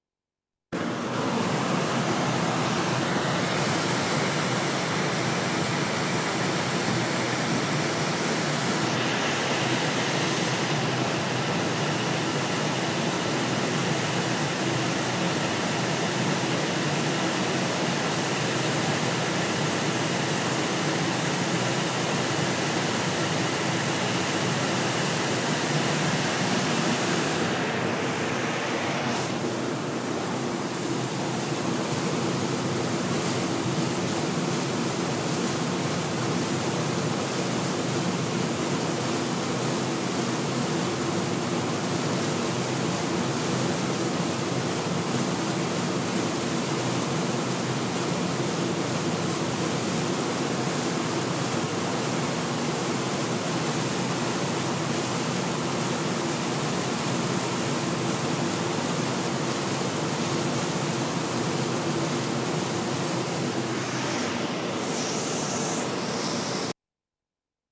描述：卡特彼勒柴油发动机的引擎室声音。
Tag: 工作的声音 现场记录 拖轮